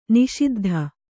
♪ niṣiddha